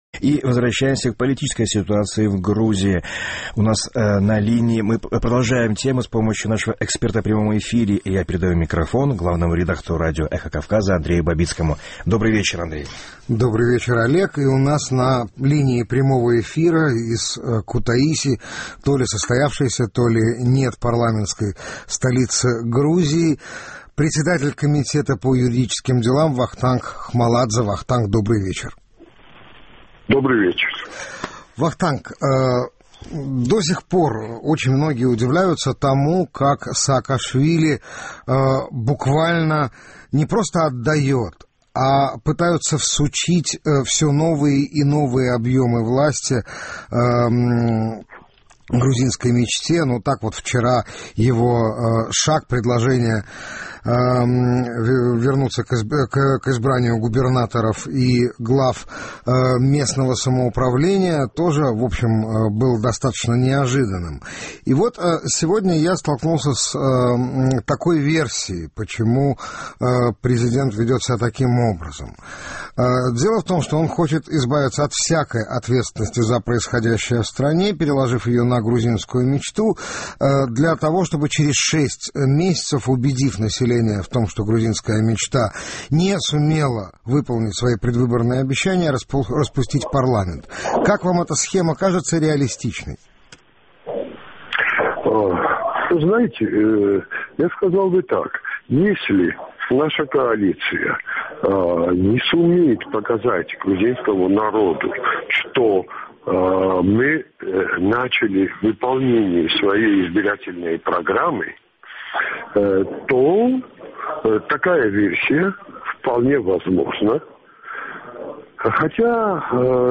У нас на линии прямого эфира из Кутаиси председатель комитета по юридическим делам Вахтанг Хмаладзе.